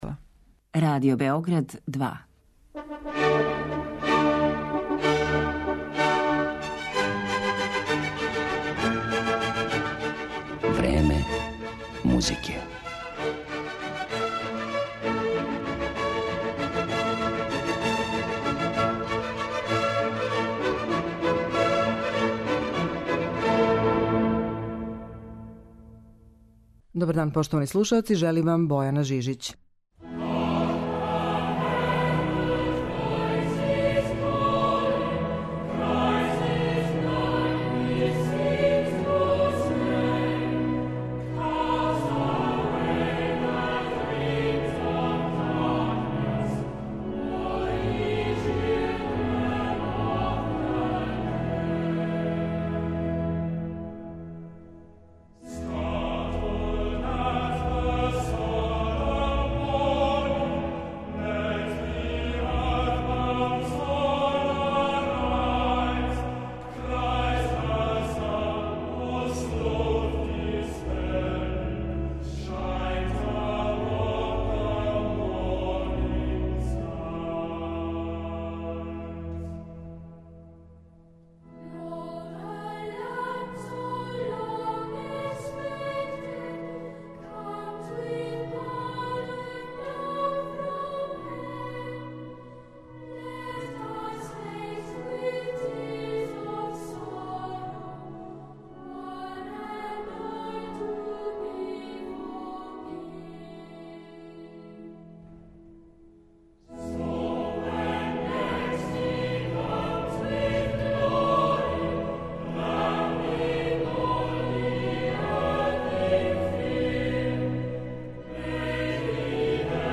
Овај изузетни вокални ансамбл